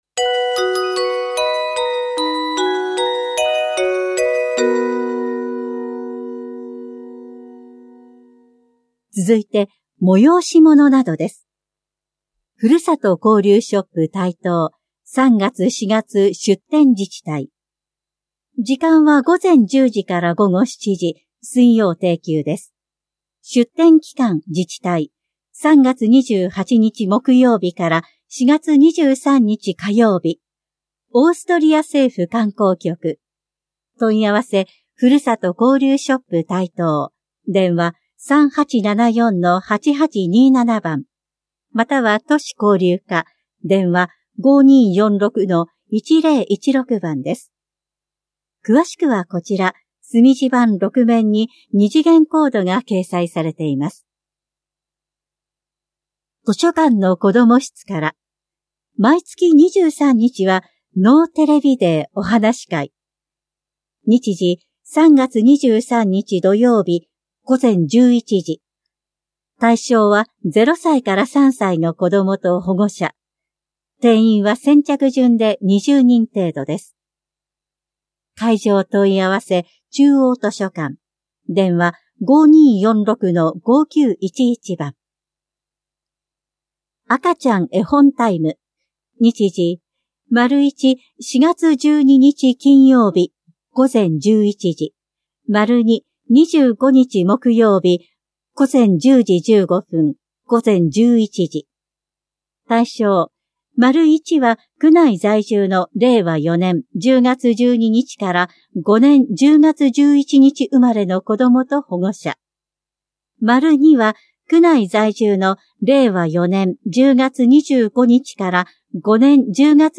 広報「たいとう」令和6年3月20日号の音声読み上げデータです。